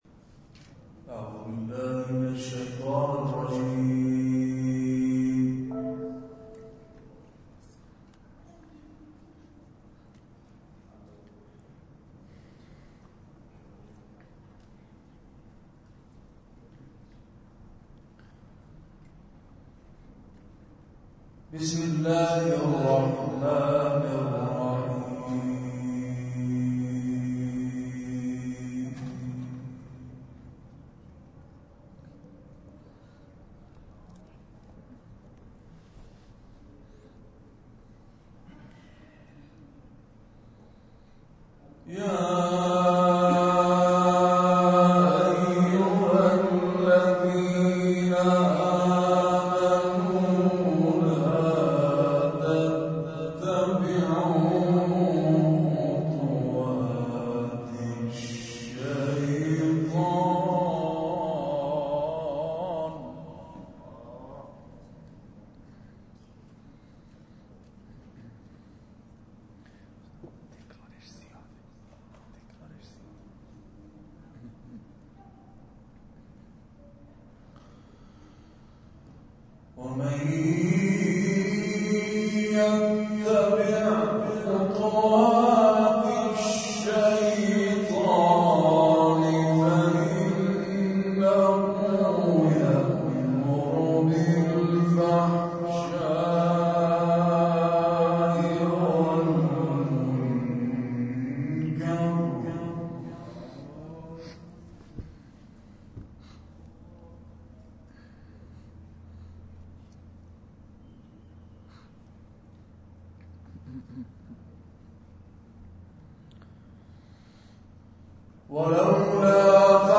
کرسی‌های تلاوت نفحات القرآن با اهدای ثواب تلاوت‌ها به حضرت عبدالعظیم(ع